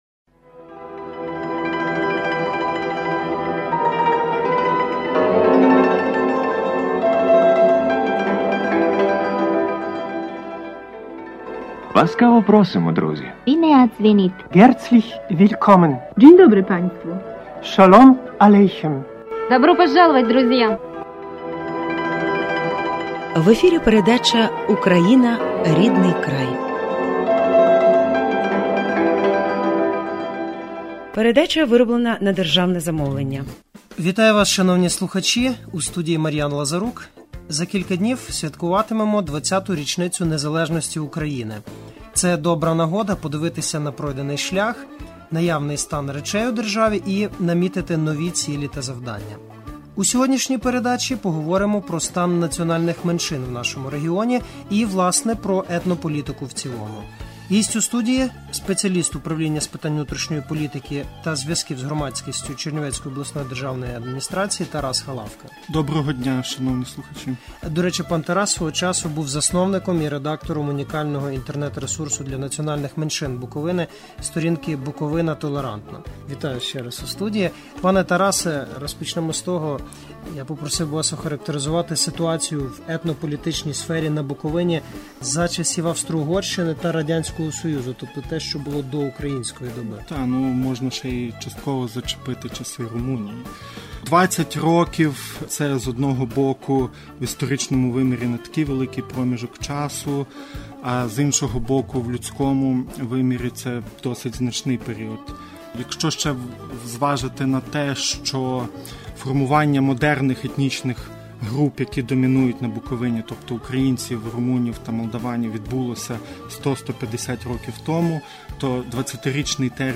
Гість у студії